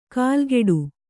♪ kālgeḍu